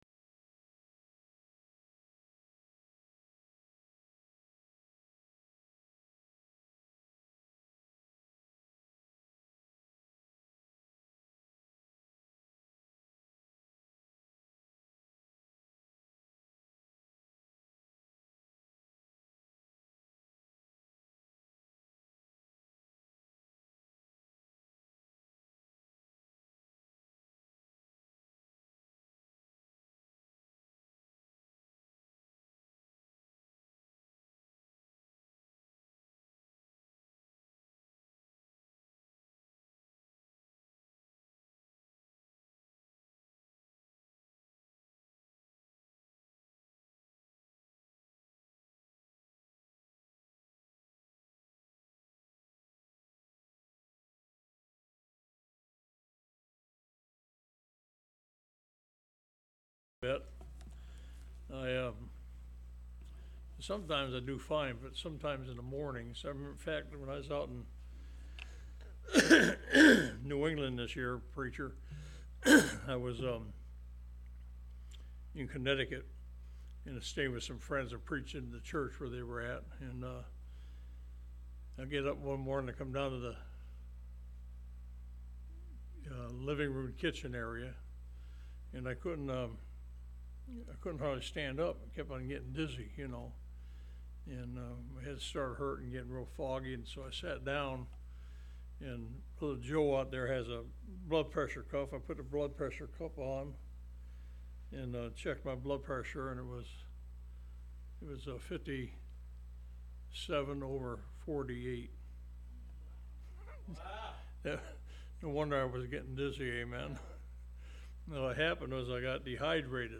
Online Sermons – Walker Baptist Church
From Series: "AM Service"